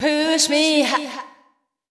VOICEGRL156_HOUSE_125_A_SC2.wav